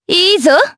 Lewsia_B-Vox_Happy4_jp.wav